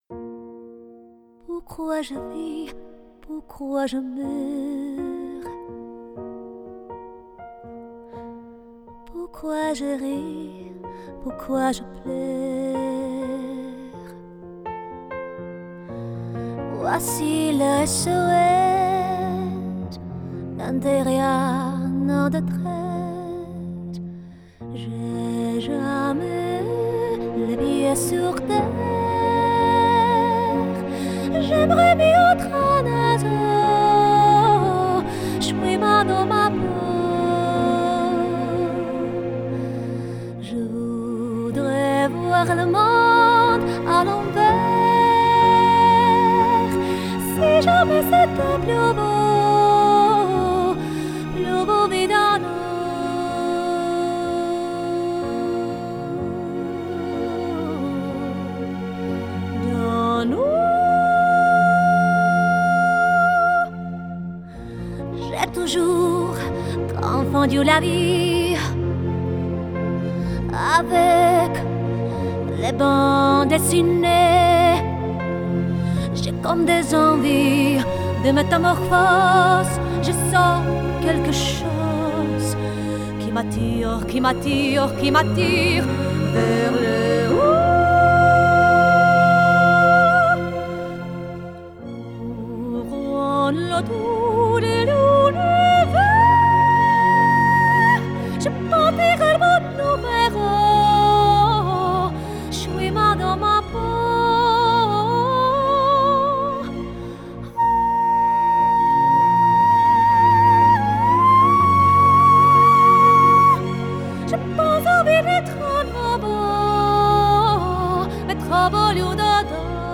Thanks to tube based design, your voice will be on face and slightly compressed and will sit nicely in a mix.
Female Voice (unprocessed)
Female-Voice.wav